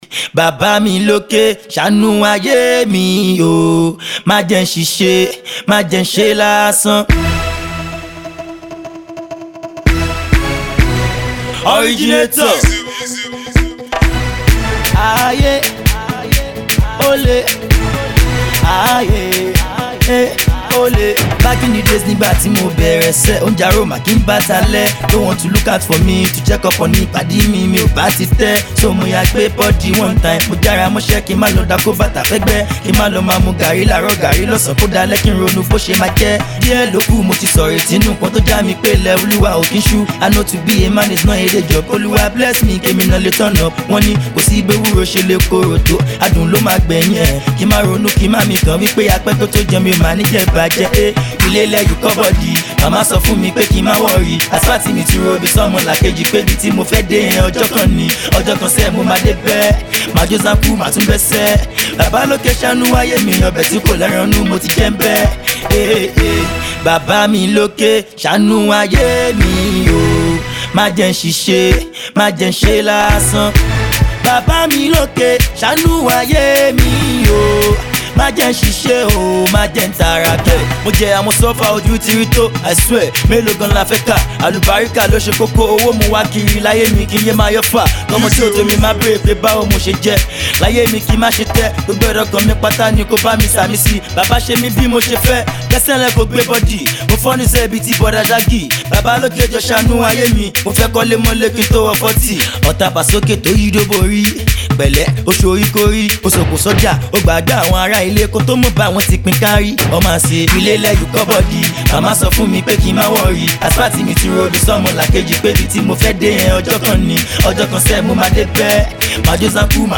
radio and club-friendly banger